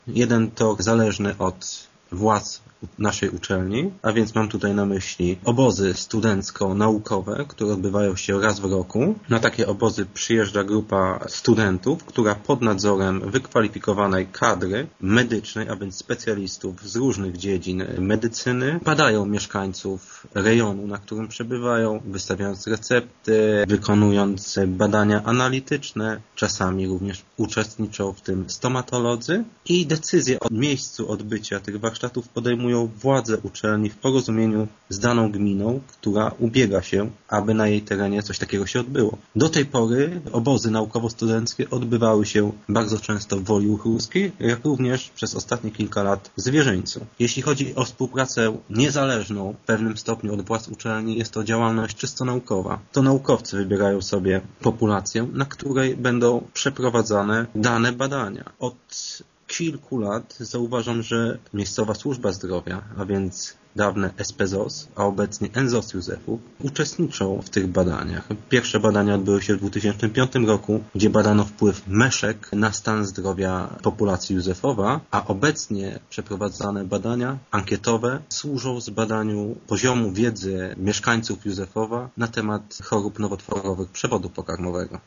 To pierwszy etap ewentualnej współpracy z gminą Józefów. „W przyszłości mogłaby ona przebiegać dwutorowo” - mówi Informacyjnej Agencji Samorządowej